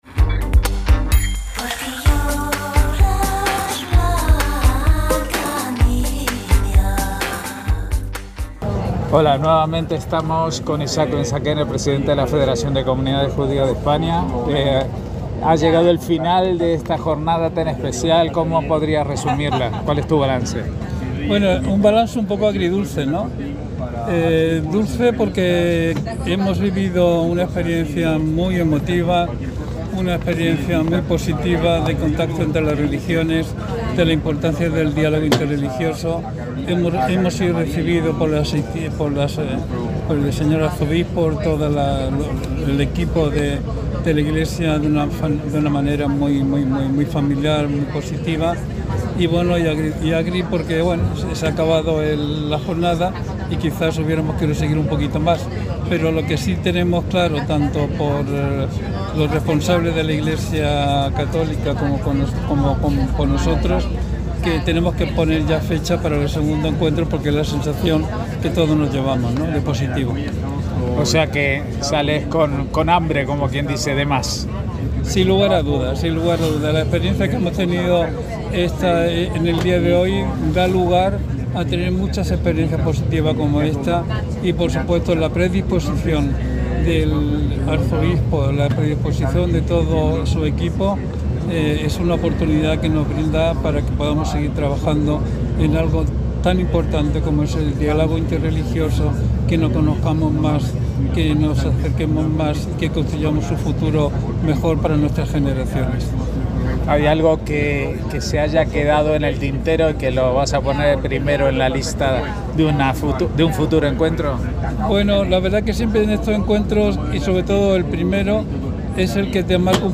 Para esta ocasión hemos recogido algunas reacciones de los asistentes a la Jornada.